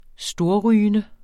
Udtale [ -ˌʁyːənə ]